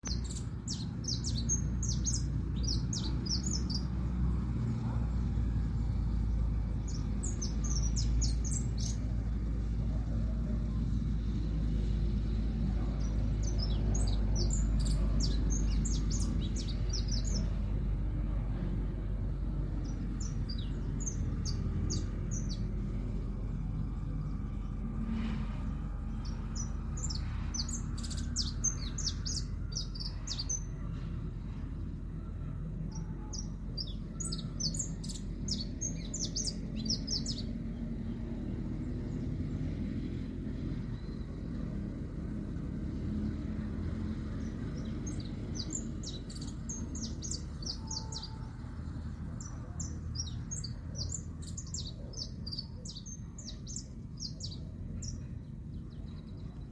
Saffron Finch (Sicalis flaveola) - EcoRegistros
Sicalis flaveola pelzelni
Sex: Male
Life Stage: Adult
Country: Argentina
Location or protected area: Santa María
Condition: Wild
Certainty: Recorded vocal